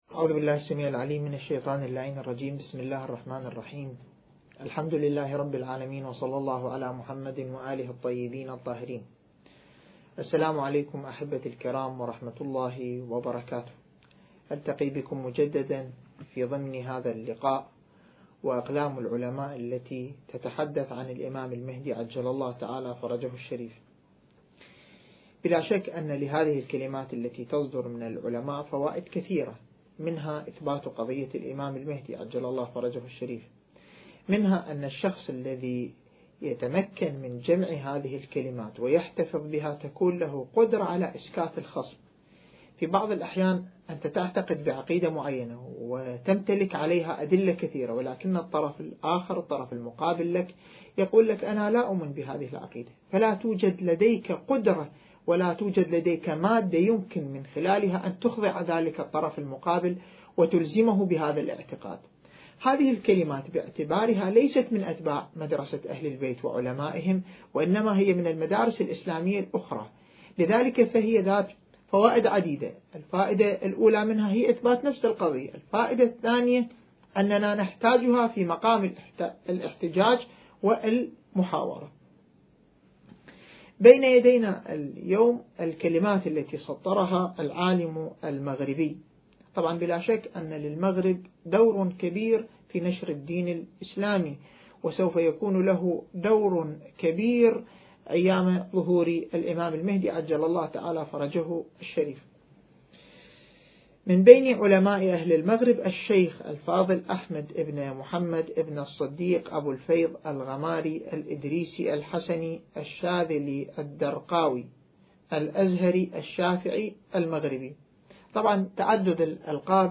مکان: دانشگاه کاشان